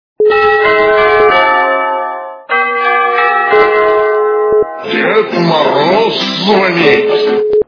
» Звуки » Смешные » Бой новогодних курантов в Новогоднюю Ночь - Дед Мороз звонит!
При прослушивании Бой новогодних курантов в Новогоднюю Ночь - Дед Мороз звонит! качество понижено и присутствуют гудки.